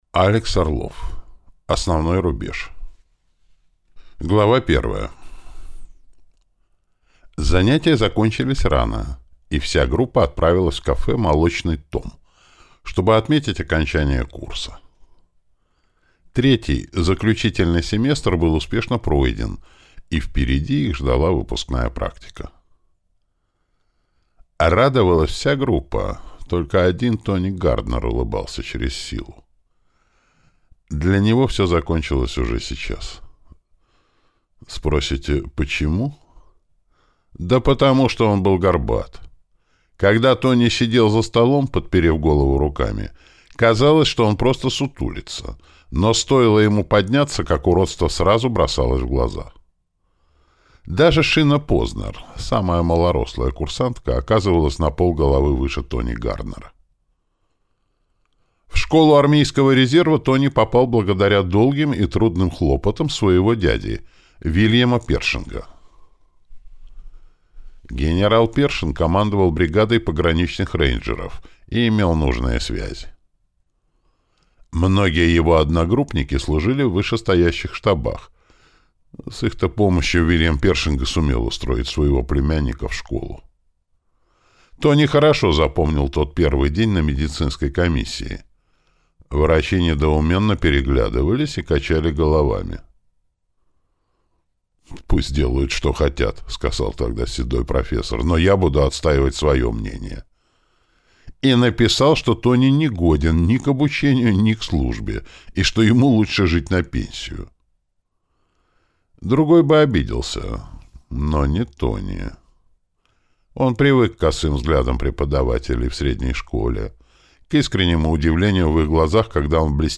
Аудиокнига Основной рубеж | Библиотека аудиокниг